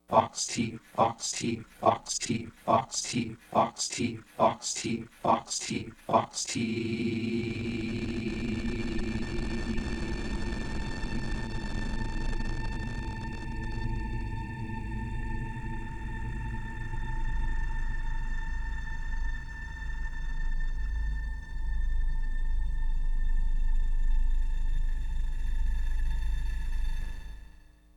Source: Text "fox teeth" (3:27-3:55)
Processing: granulated, stretch = 0:1, then 100:1 + KS = 32, F = 1000